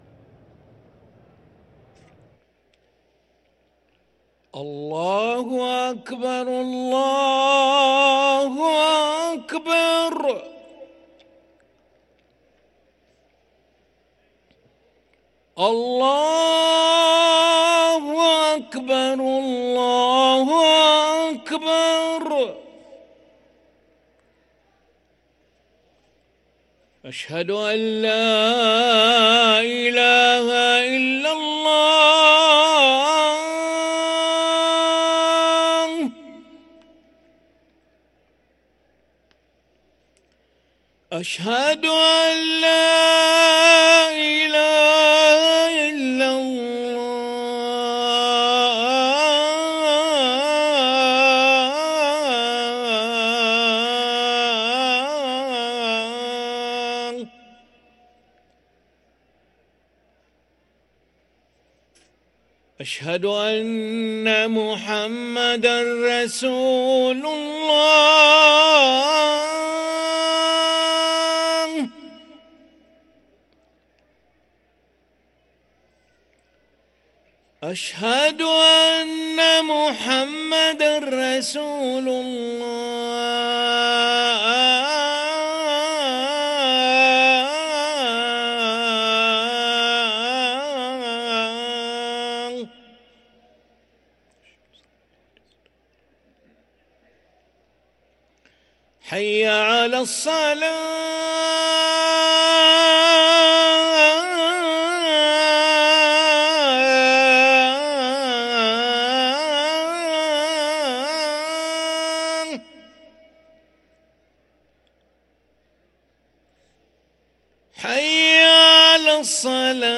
أذان العشاء للمؤذن علي أحمد ملا الأحد 10 شوال 1444هـ > ١٤٤٤ 🕋 > ركن الأذان 🕋 > المزيد - تلاوات الحرمين